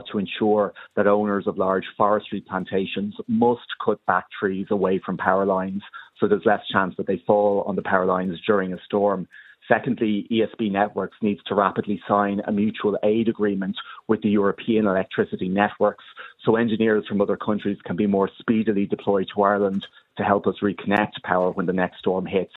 He says one step would be to enact new legislation: